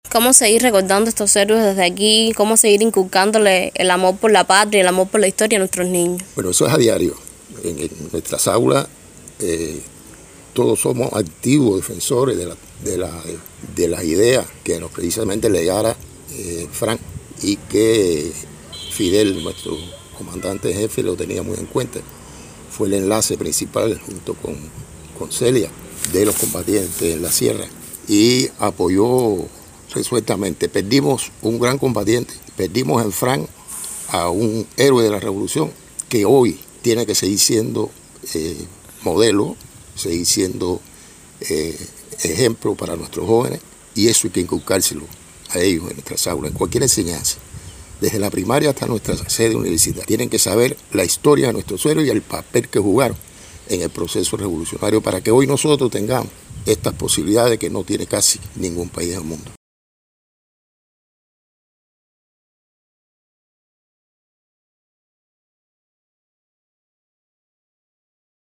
DECLARACION-2-1.mp3